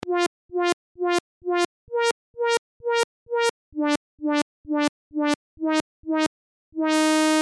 フィルターを開けていく
フィルターを開けていく.mp3